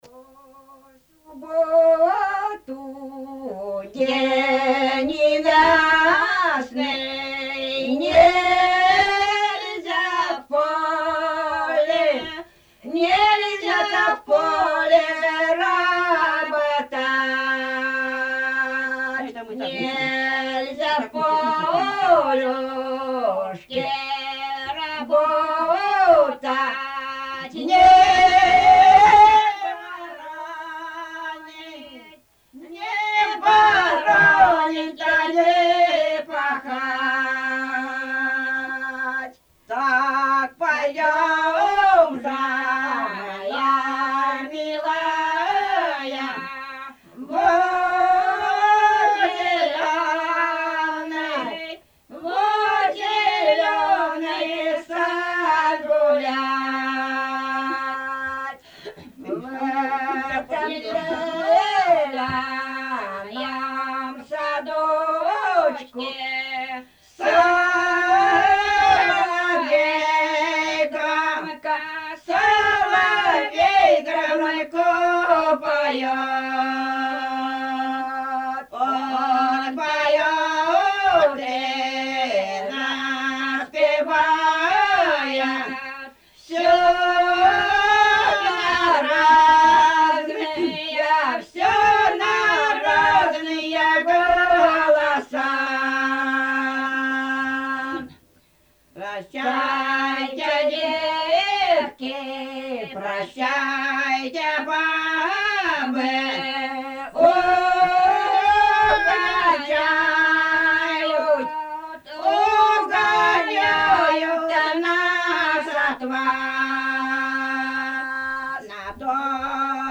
Лирические песни
Исполнитель: фольклорная группа с. Шуньга
Место записи: с. Шуньга, Медвежьегорский район, Республика Карелия.